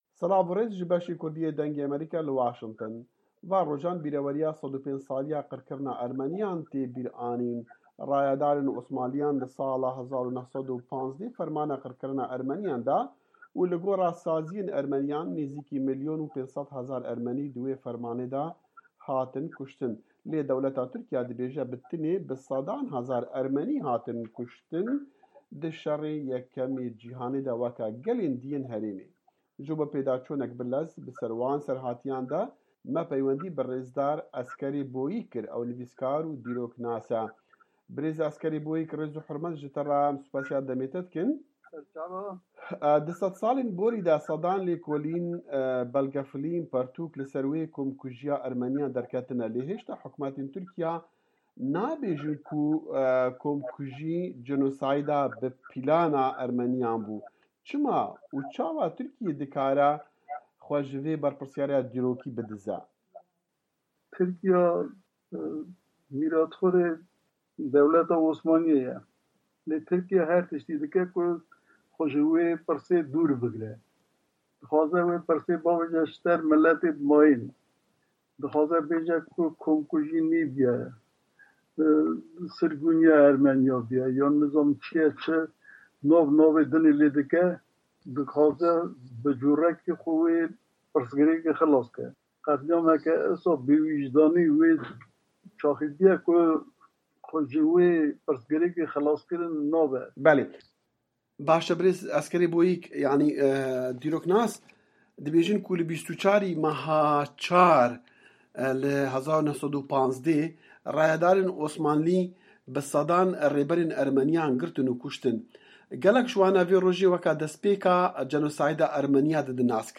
Hevpeyvî